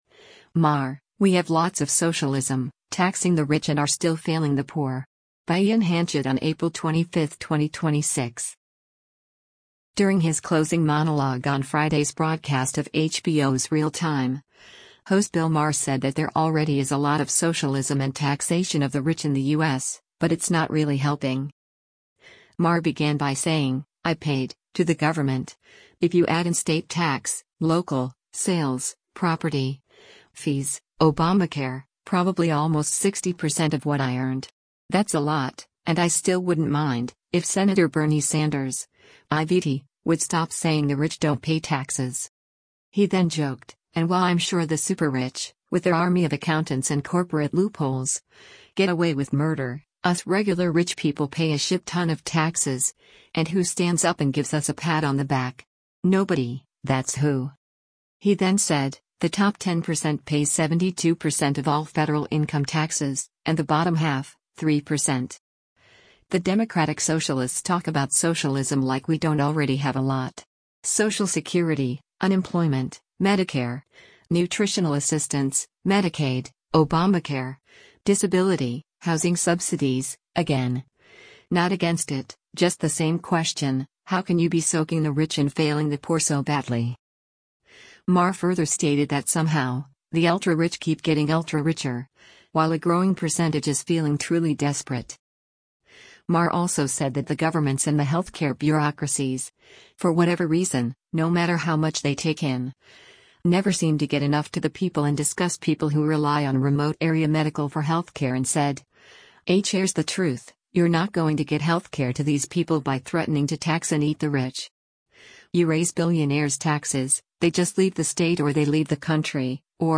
During his closing monologue on Friday’s broadcast of HBO’s “Real Time,” host Bill Maher said that there already is a lot of socialism and taxation of the rich in the U.S., but it’s not really helping.